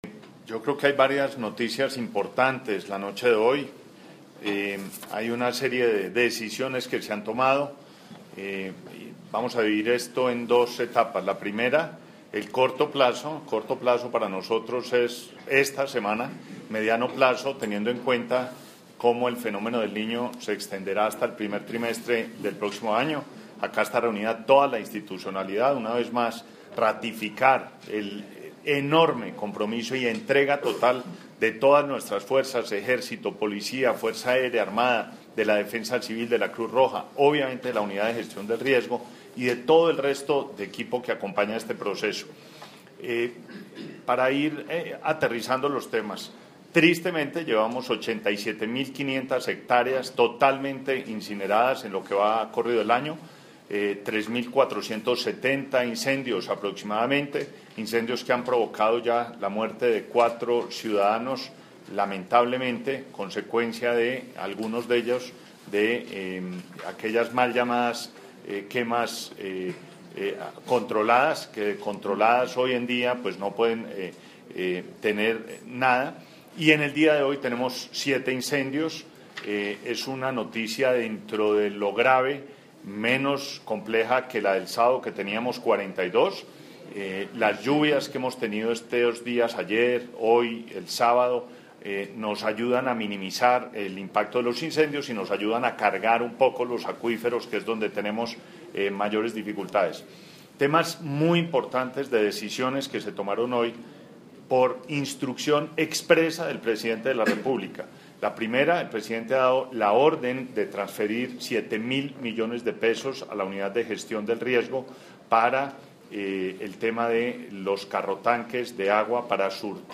Declaraciones del Ministro de Ambiente y Desarrollo Sostenible, Gabriel Vallejo López audio